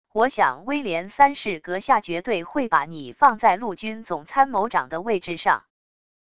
我想威廉三世阁下绝对会把你放在陆军总参谋长的位置上wav音频生成系统WAV Audio Player